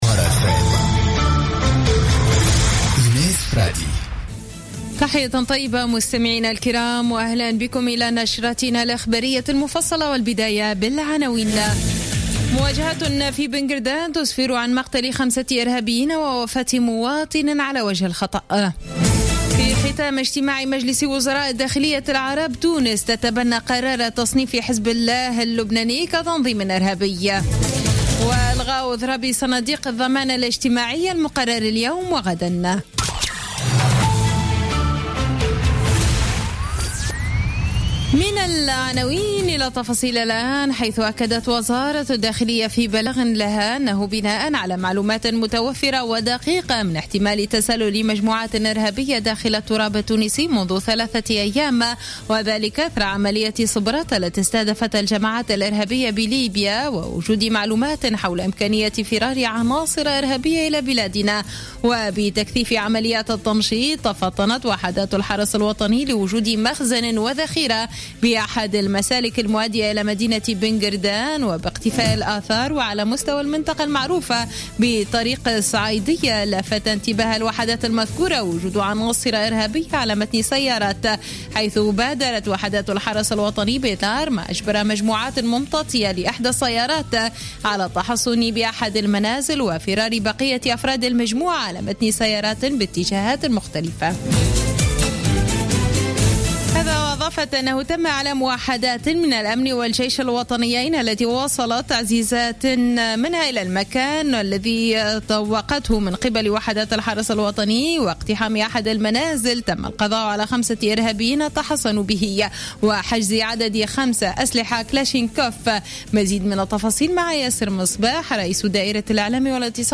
نشرة أخبار منتصف الليل ليوم الخميس 3 مارس 2016